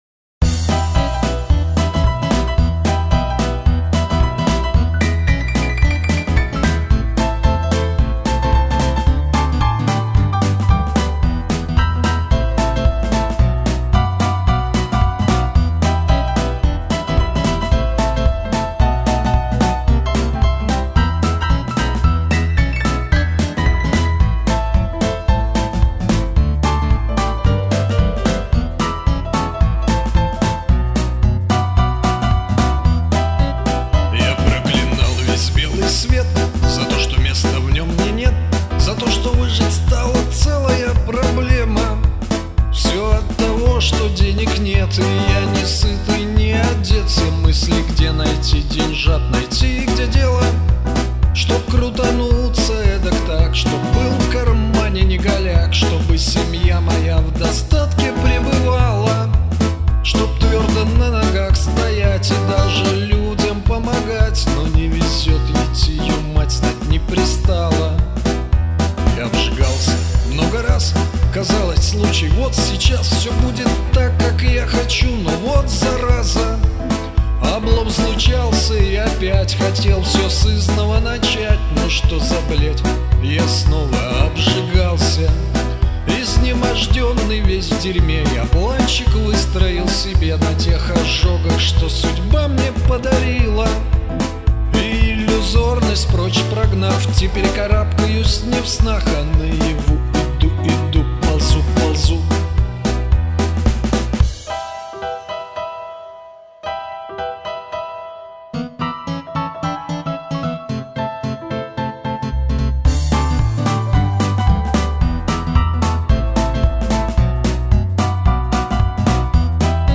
Авторские песни